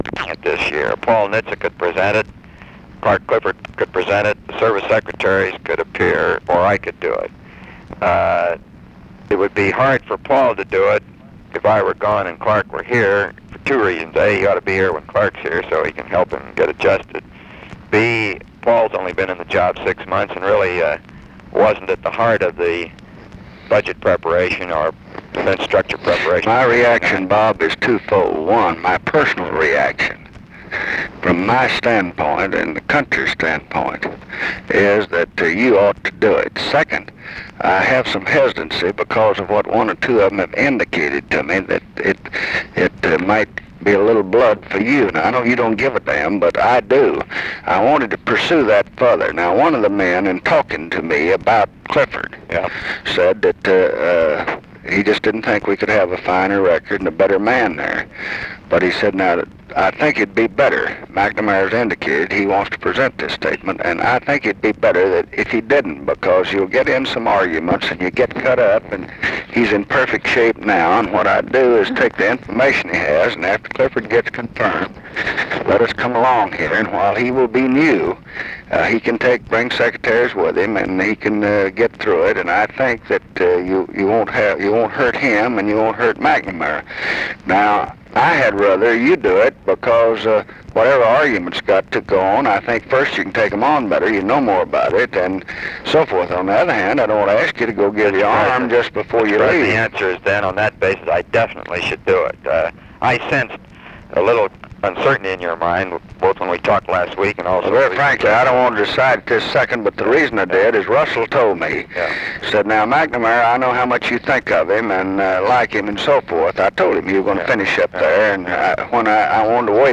Conversation with ROBERT MCNAMARA, January 24, 1968
Secret White House Tapes